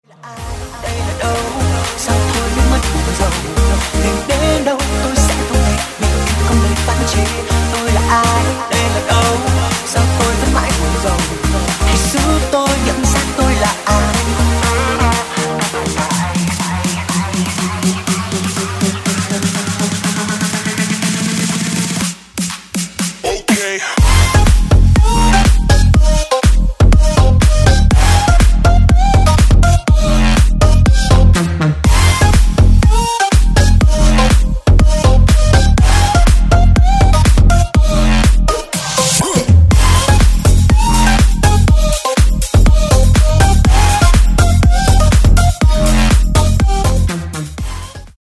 G House Version